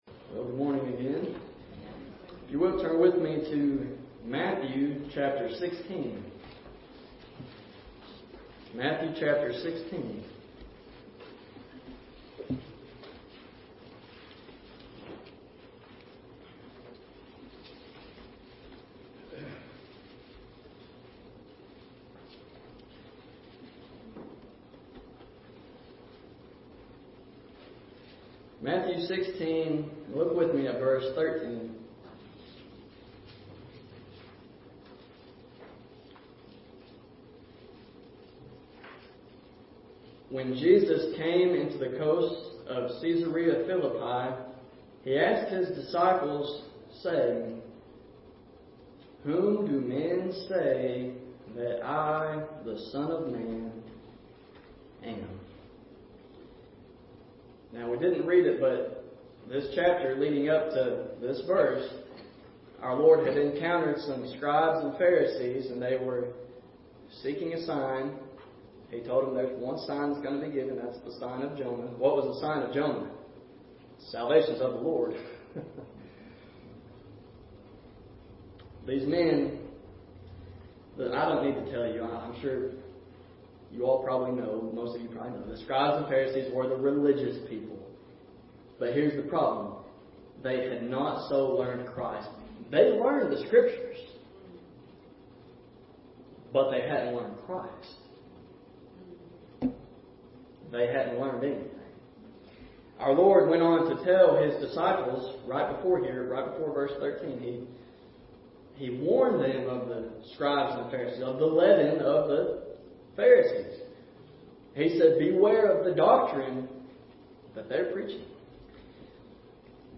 Thou Art The Christ | SermonAudio Broadcaster is Live View the Live Stream Share this sermon Disabled by adblocker Copy URL Copied!